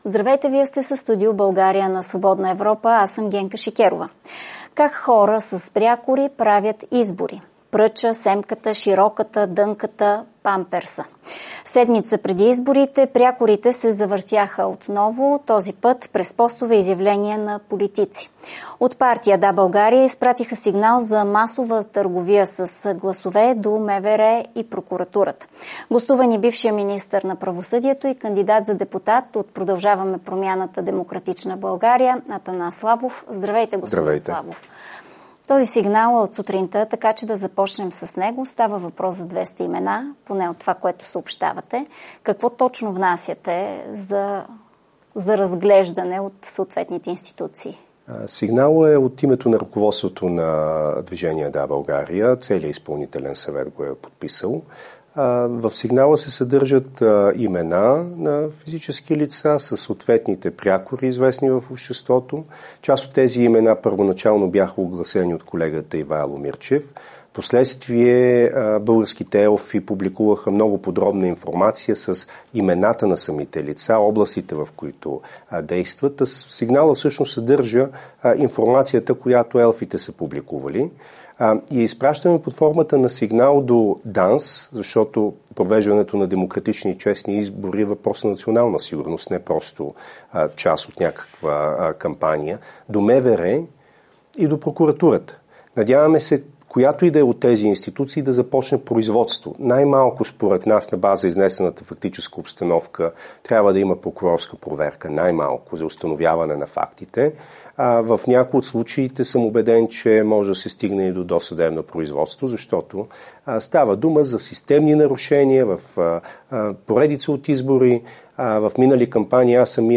Партията "Да, България" изпрати сигнал за масова търговия с гласове до МВР и прокуратурата. Бившият министър на провосъдието и кандидат за депутат от ПП-ДБ Атанас Славов е гост на Генка Шикерова.